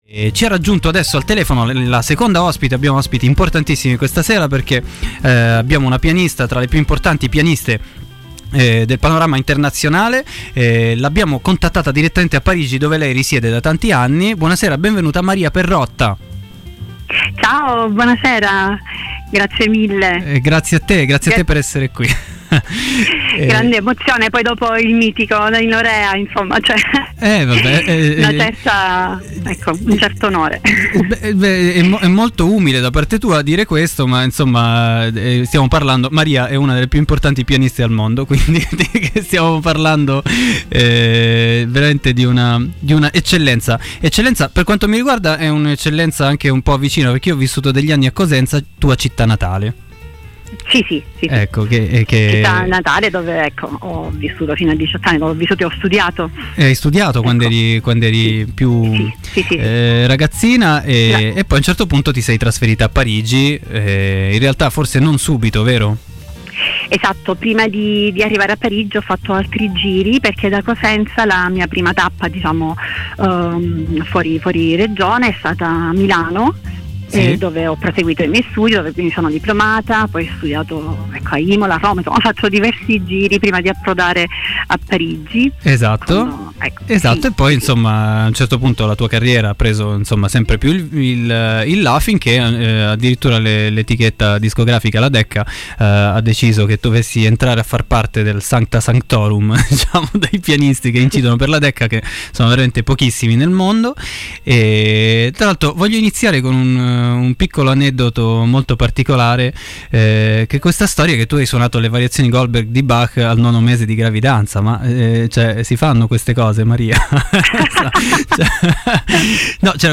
intervista telefonicamente